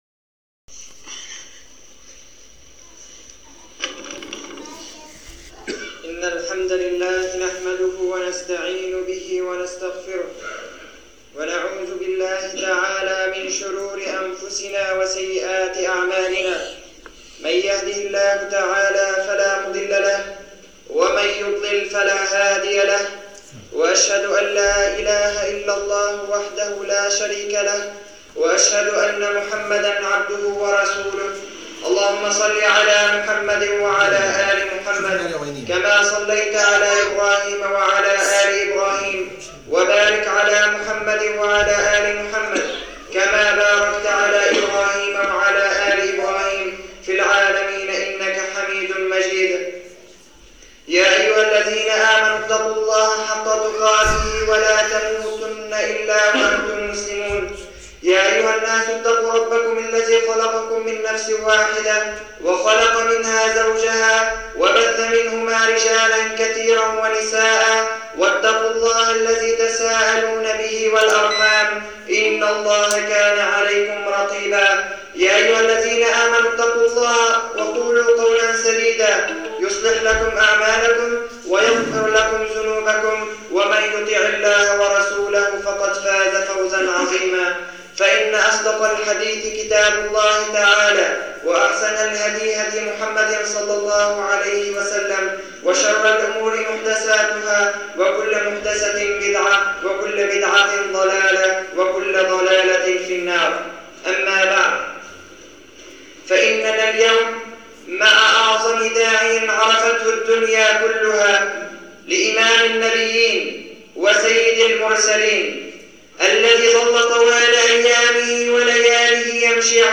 [خطبة جمعة] أعظم الدعاة ١
المكان: مسجدإيزال-الضنية الموضوع: أعظم الدعاة ١ تحميل